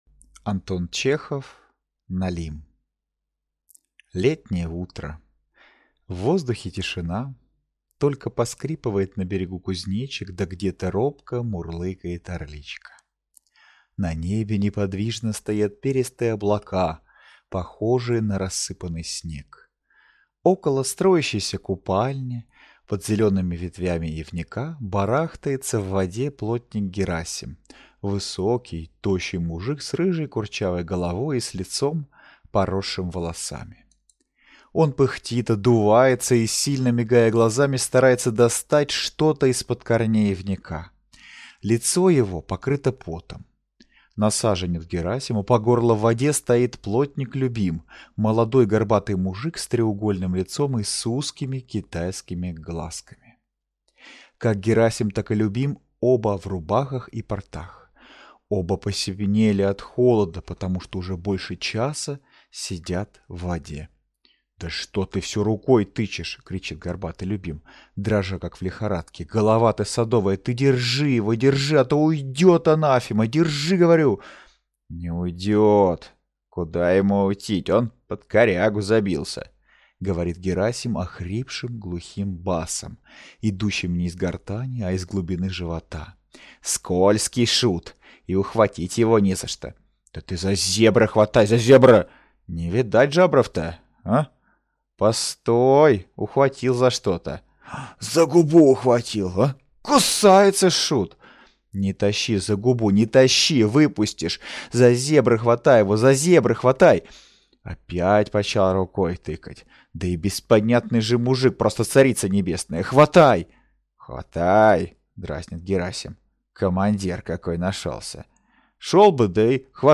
Аудиокнига Налим | Библиотека аудиокниг